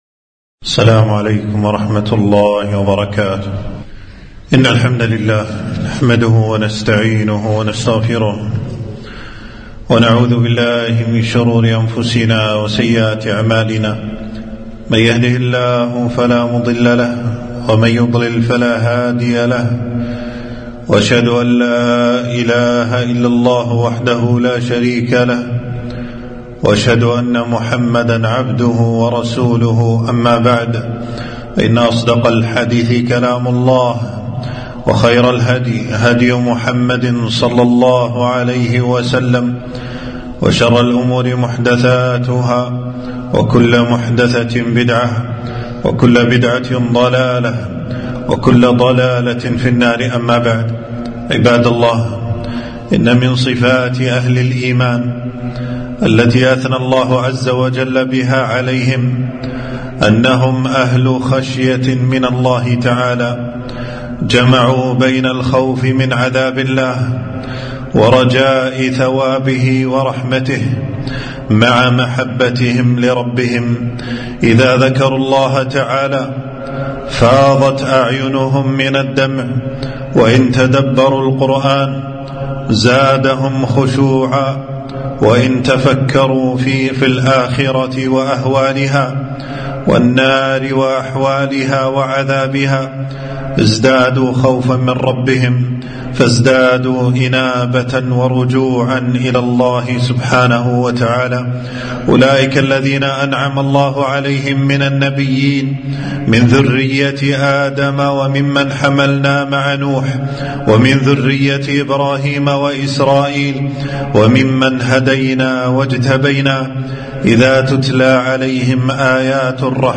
خطبة - الخشية من الرحمن من صفات أهل الإيمان